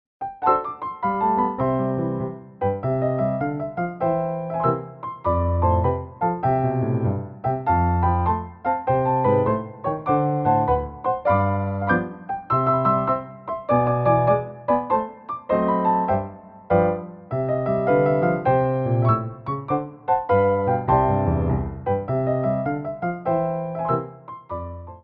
Petit Allegro 1
6/8 (16x8)